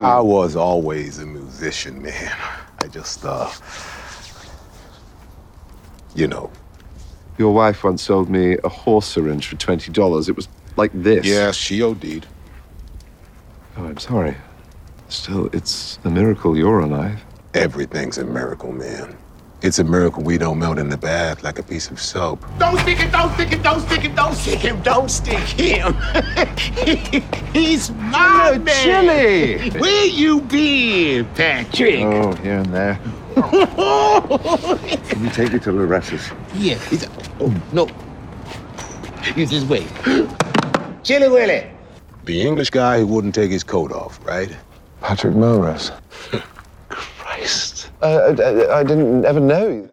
American Accent Reel
Acting, Emotions, Energetic, Authoritative, Character, Versatile